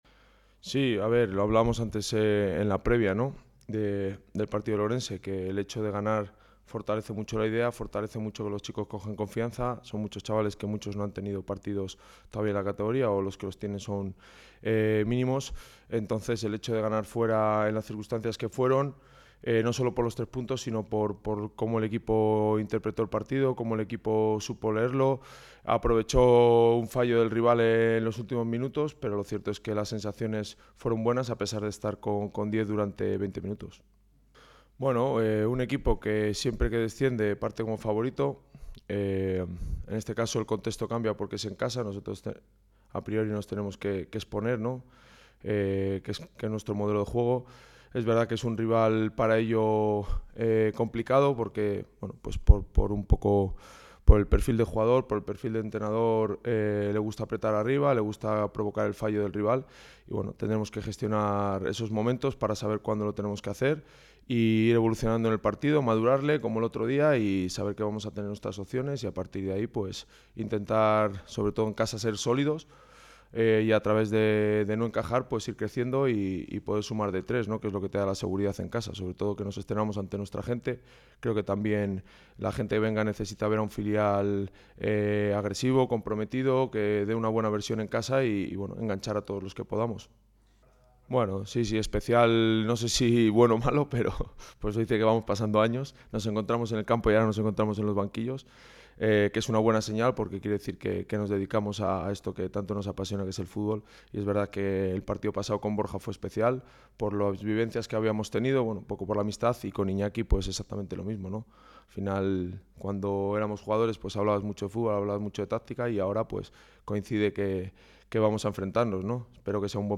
Ruedas de prensa